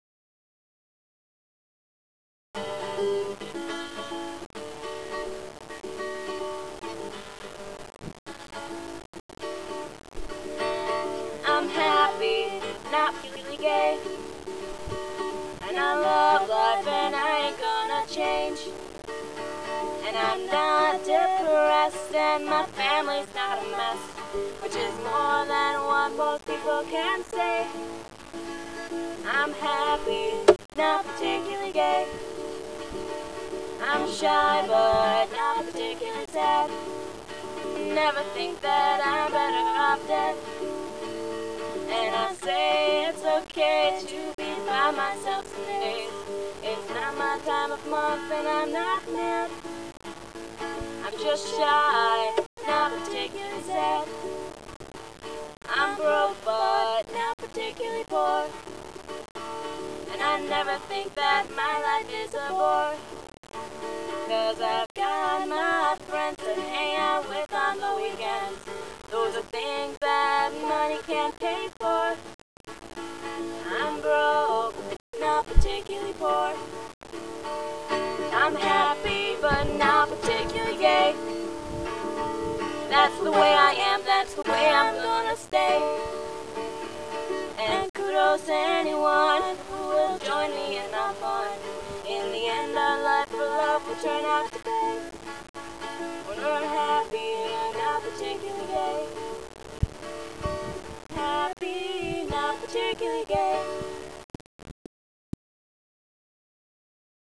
Hopefully updating this sometime soon (got the software and mic, just need to get off my butt and get it going) Once again sorry the sound quality is insanely bad.
In this recording I keep speeding up :-P. Maybe someday i'll upload a new one-